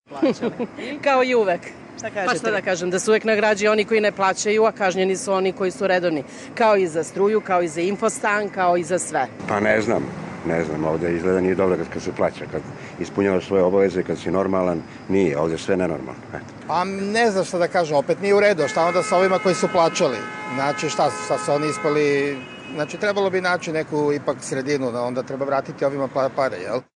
Građani o pretplati